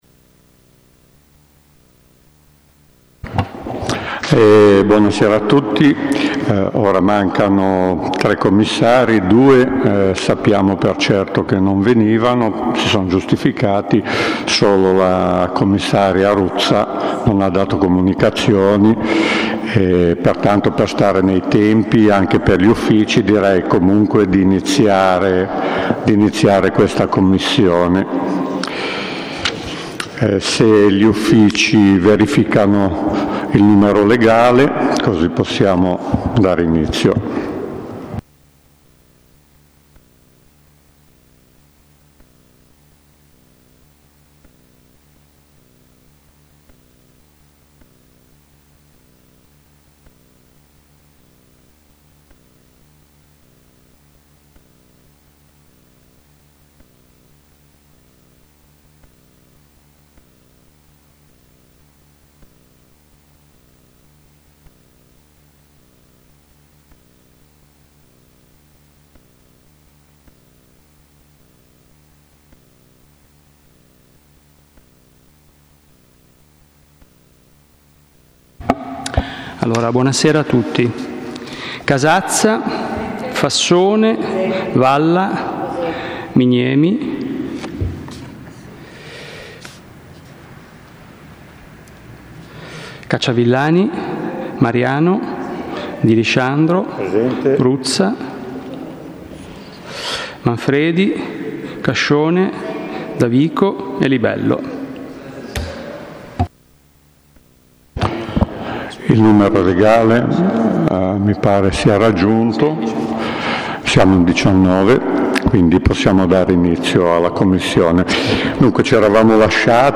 Commissione consiliare o Consiglio Comunale: Municipio III - Commissione 1
Luogo: piazza Manzoni, 1 - Sala del Consiglio